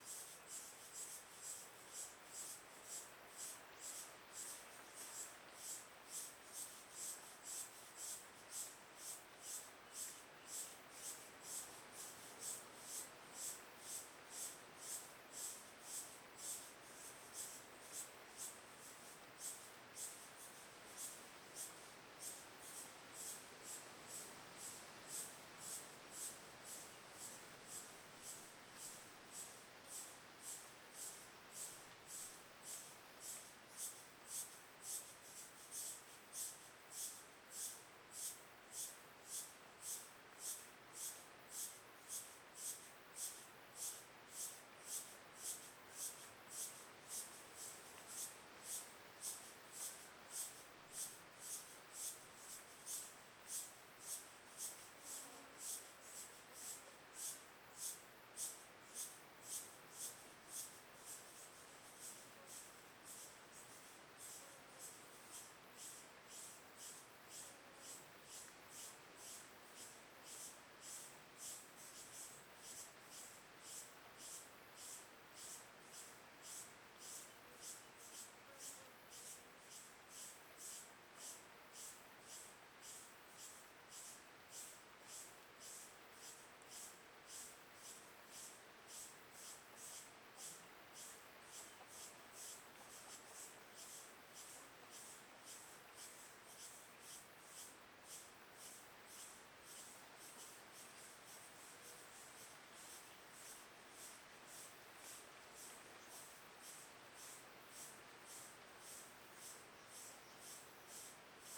CSC-05-161-OL- Mata fechada a tarde com cigarras ritmicas.wav